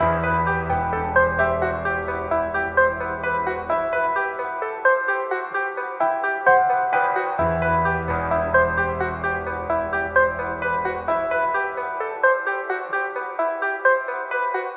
Tag: 130 bpm Trap Loops Piano Loops 2.48 MB wav Key : E